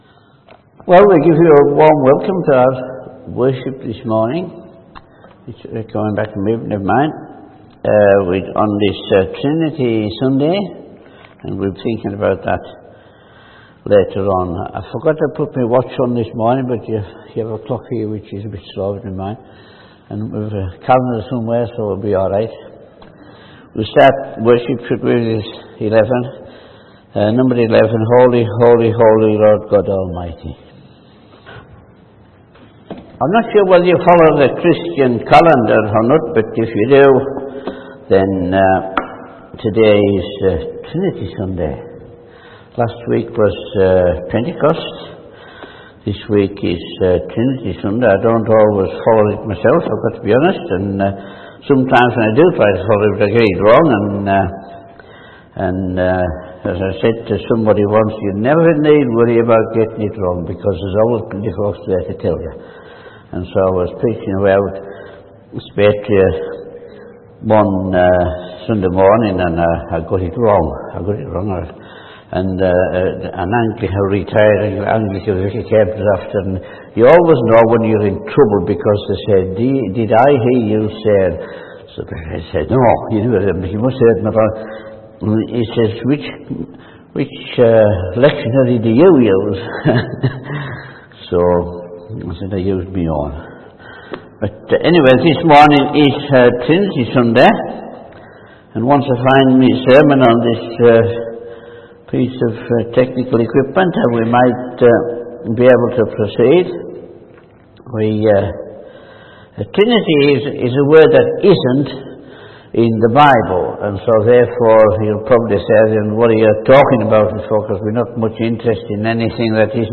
A message from the series
From Service: "9.00am Service"